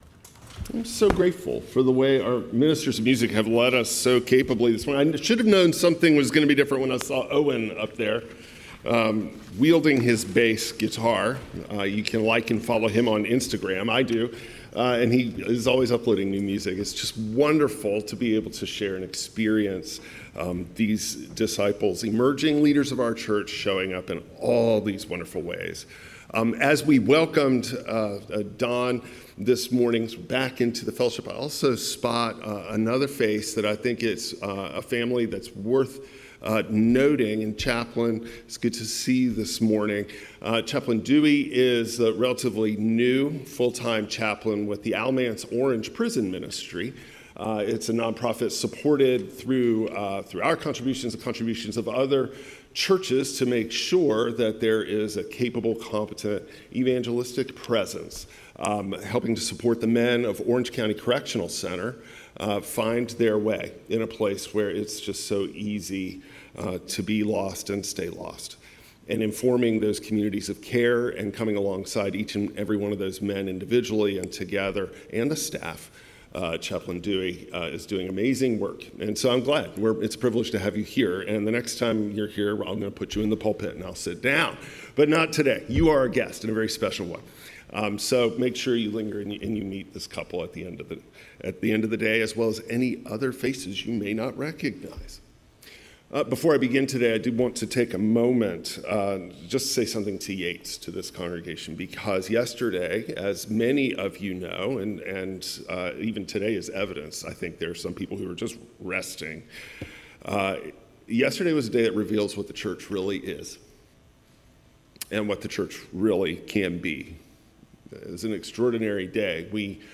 John 4:5–42 Service Type: Traditional Service Jesus meets a Samaritan woman at the well and transforms her shame into testimony.